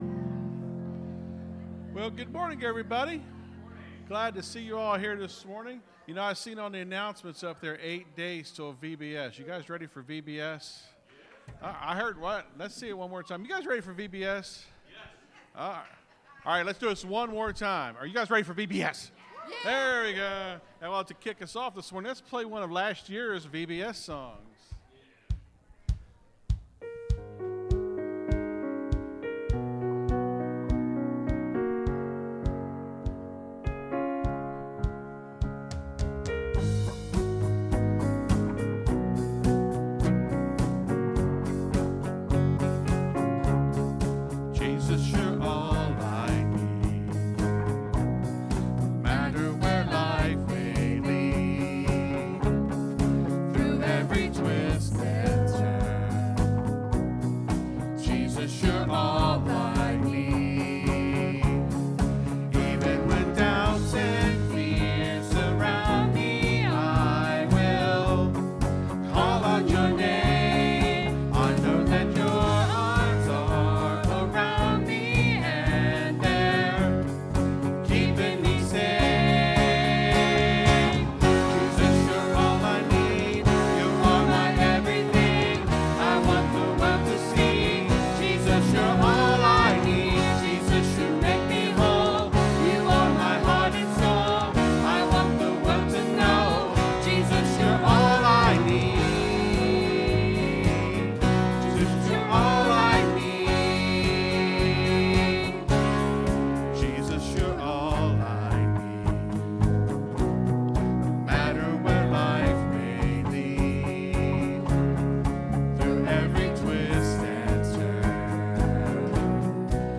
(Sermon starts at 22:25 in the recording).